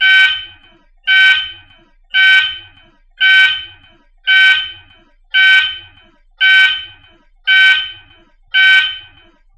alarm_tips2.mp3